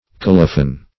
Colophon \Col"o*phon\ (k[o^]l"[-o]*f[o^]n), n. [L. colophon